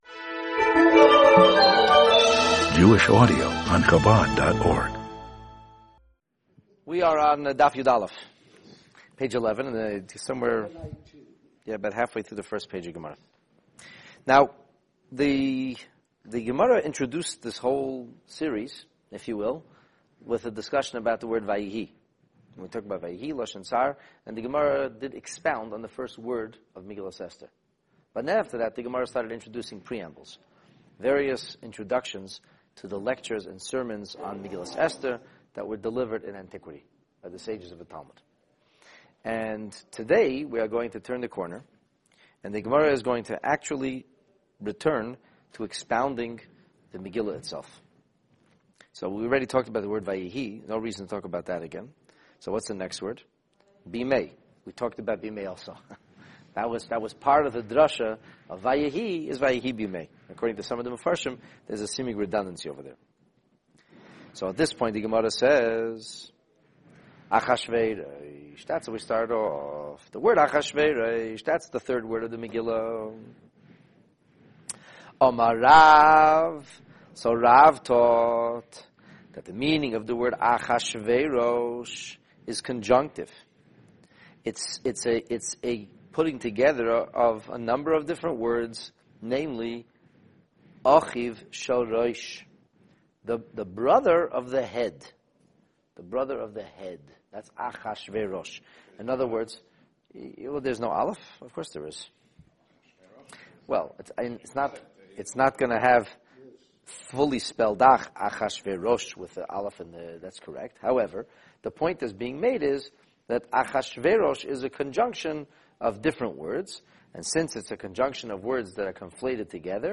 Talmud: Megillah Story ch.1, Lesson 6 Daf/Page 11a Delve into the opening words of Megillat Esther in this fascinating Talmud class, which reads into names with incredible depth and insight. Get a peek into the twisted soul and psyche of this ancient Emperor whose royal ambition, ruthless military conquest, and unbridled savagery deeply affected Jewish life in antiquity.